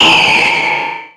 Cri de Méga-Altaria dans Pokémon Rubis Oméga et Saphir Alpha.
Cri_0334_Méga_ROSA.ogg